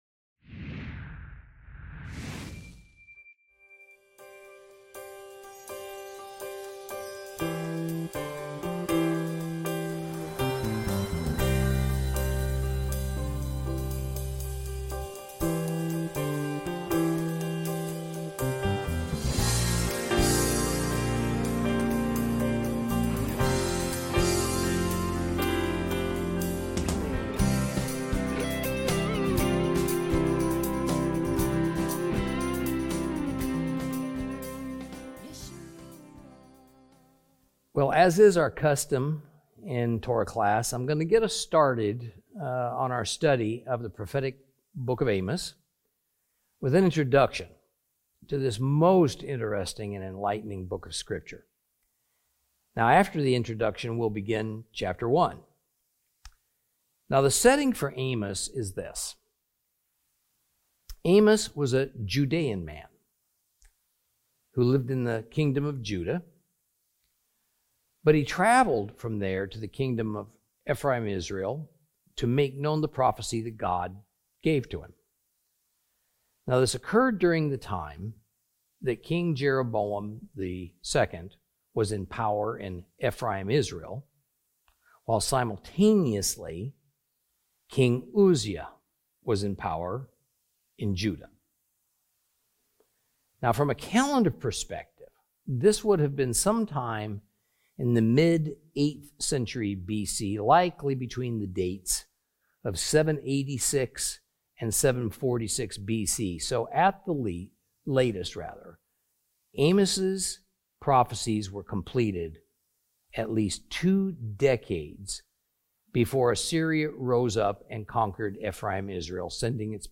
Teaching from the book of Amos, Lesson 1 Introduction and Chapter 1.